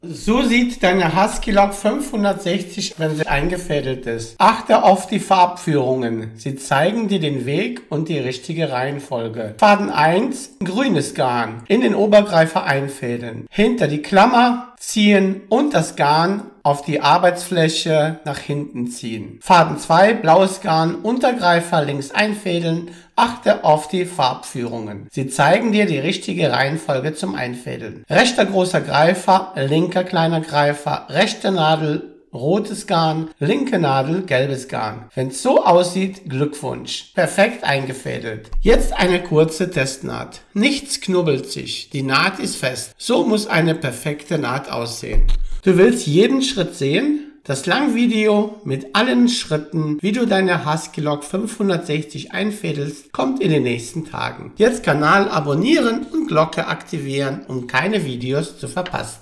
Seit 2 Tagen Hall bei Aufnahme im Audio
Ich habe seit 2 Tagen bei jeder Aufnahme eines Videos oder Audios immer einen starken Hall oder ein Echo sowohl am Iphone als auch am Mac, im Hintergrund.
Anbei die Datei, die ich heute in der Küche mit dem iPhone und dem externen Rode NT-USB erstellt habe. Am PC mit Audacity hört sich das noch schlimmer an.